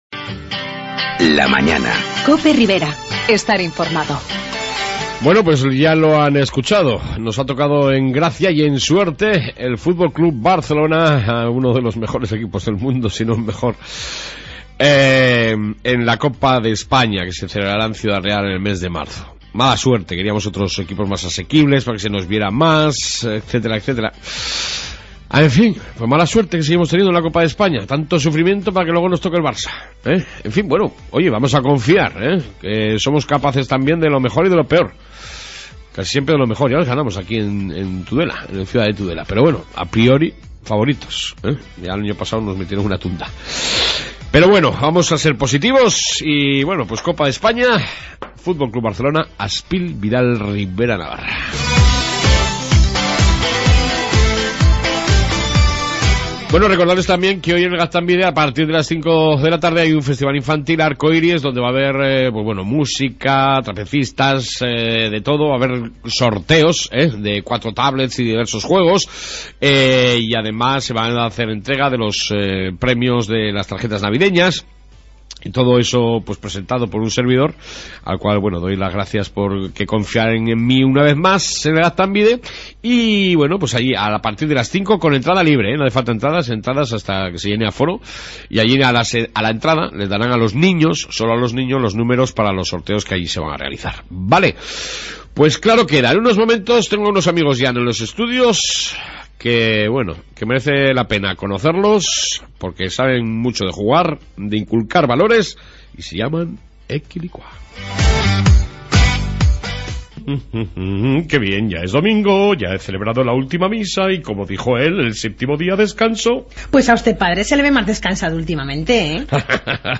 AUDIO: En esta 2 parte entrevista con Ekilikua